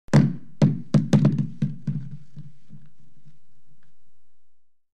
Звуки еды
Звук удара яблока о поверхность